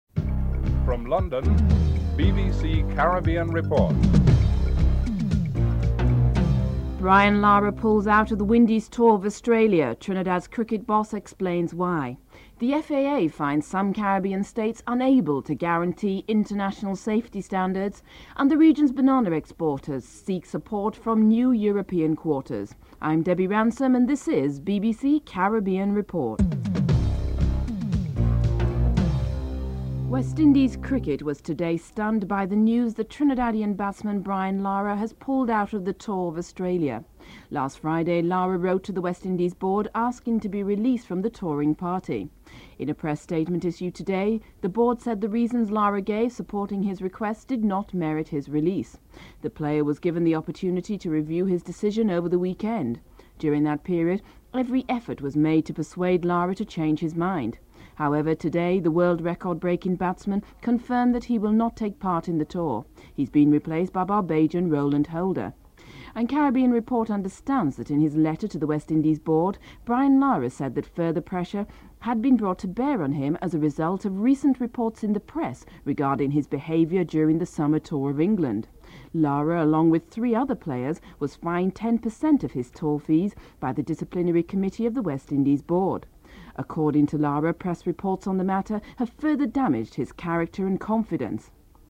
One of the persons tipped for this top administrative position of Director General of OECS, former Dominican Trade Minister Charles Maynard, comments on the issue.